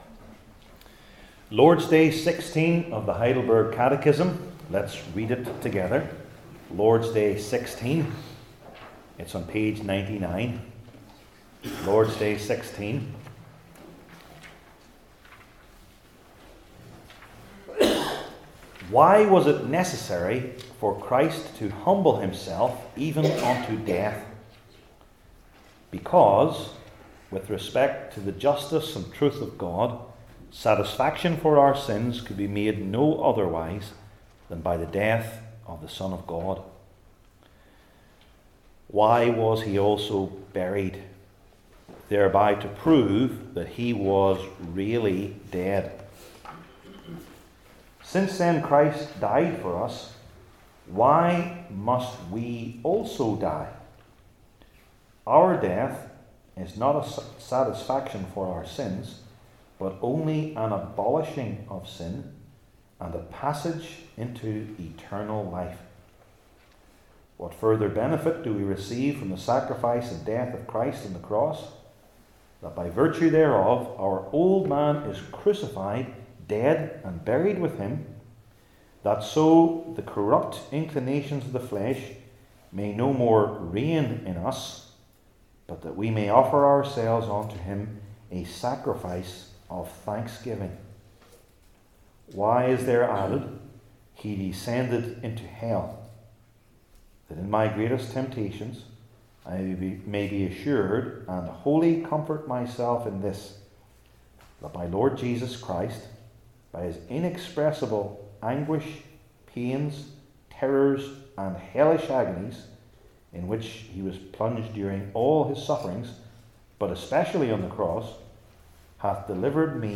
John 19:28-42 Service Type: Heidelberg Catechism Sermons I. With Regard to His Heavenly Father II.